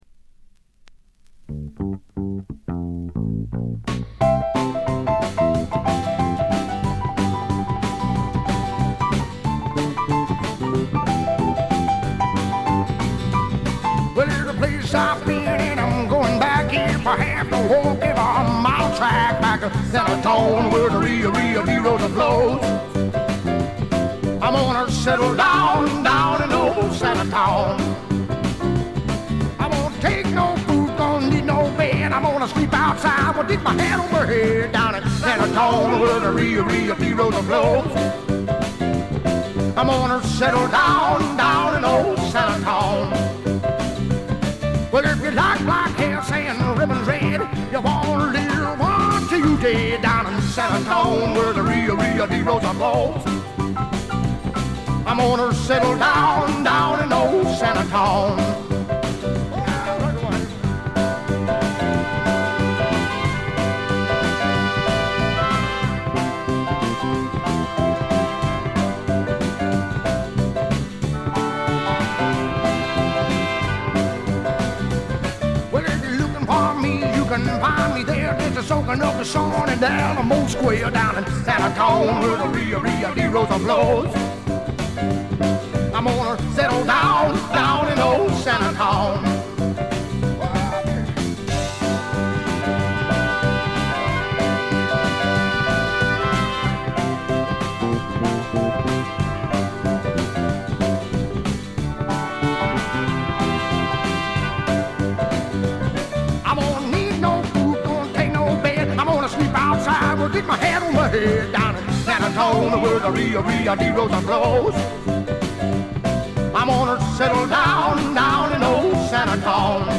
部分試聴ですが、ほとんどノイズ感無し。
肝心の音はといえば南部の湿った熱風が吹きすさぶ強烈なもの。
試聴曲は現品からの取り込み音源です。
Fiddle